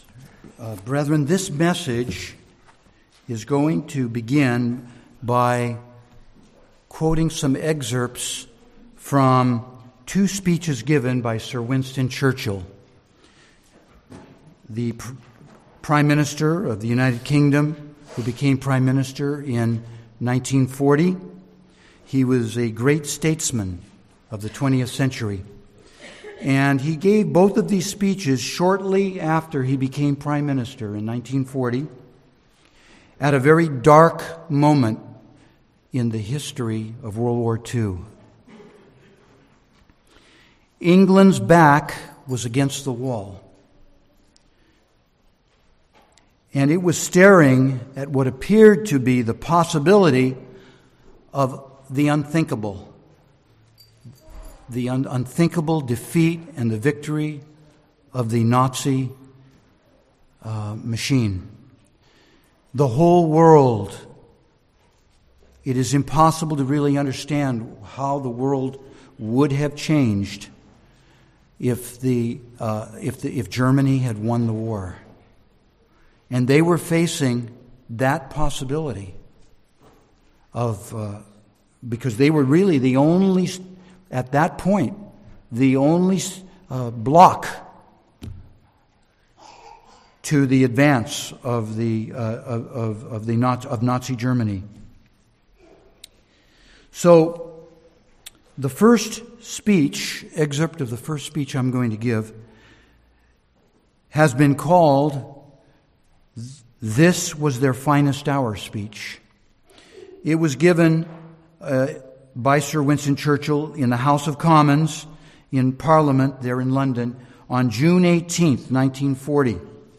Listen to this sermon to discover how we should be doing our part in support of preaching the gospel, and preparing a people.